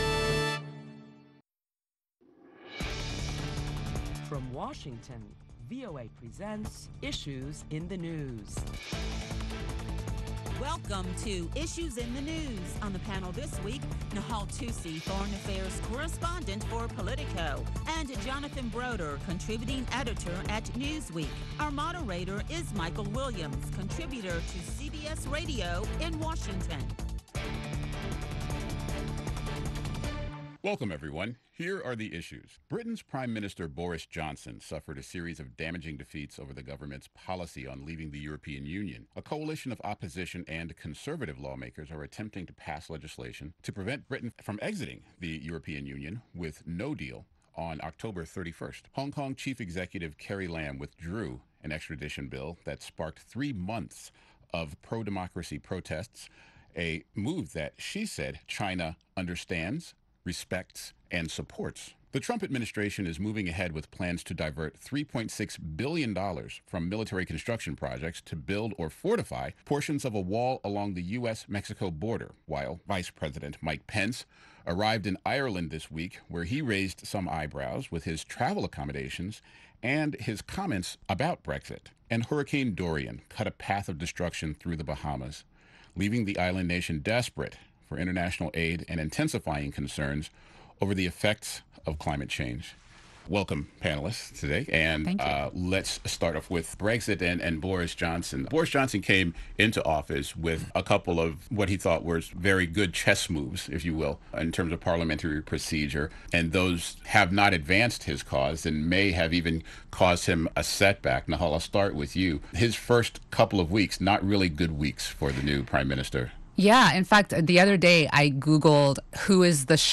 Listen to a panel of prominent Washington journalists as they deliberate the latest top stories that include recovery efforts in the Bahamas after Hurricane Dorian’s devastation…and why protestors in Hong Kong are keeping up the pressure.